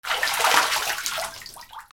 水から上がるときの水音 2